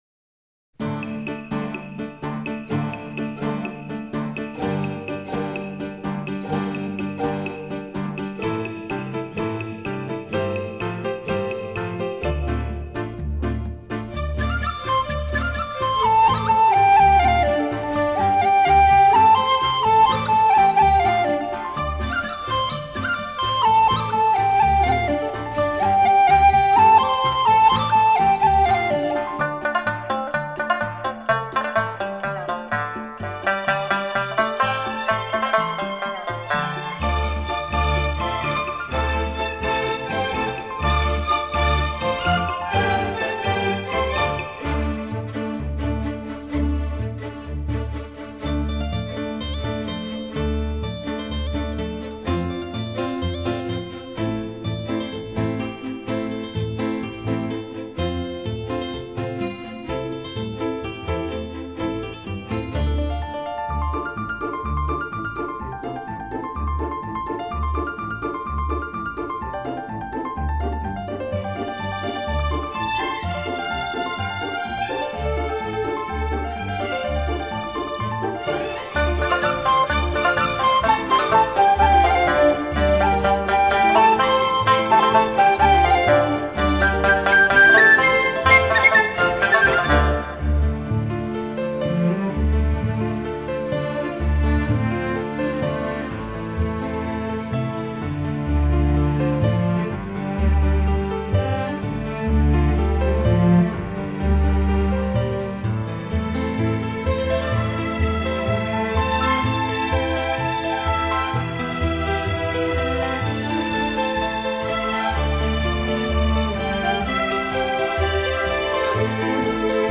以中国民族乐器为主奏，辅以西洋管弦乐器及电声乐器。
最新数码系统录制，音响爆棚，旋律悦耳，您会从音乐中感受到当地人民的生活气息和地方风情。
达斡尔族民歌